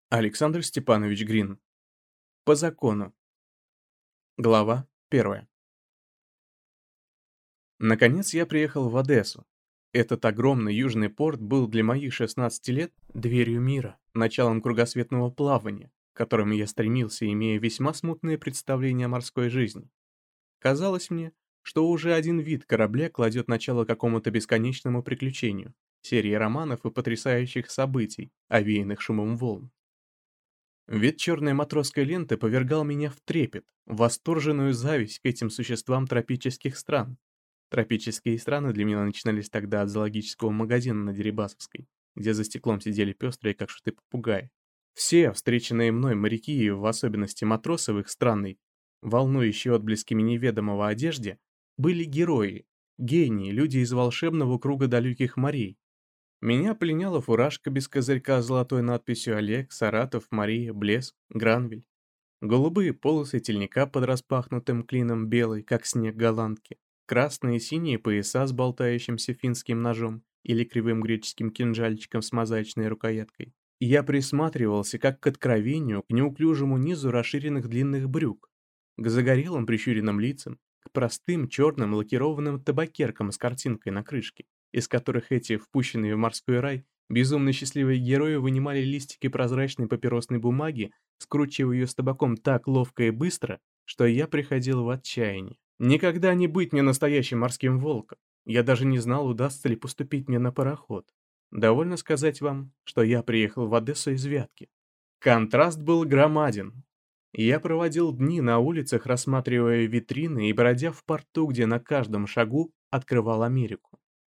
Аудиокнига По закону | Библиотека аудиокниг